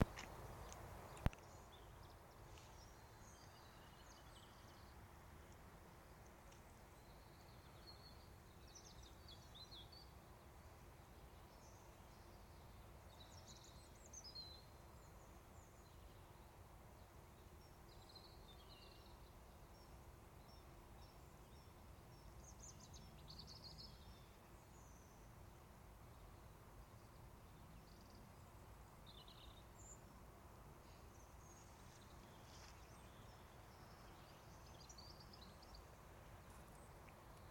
birdsong at Fibbersley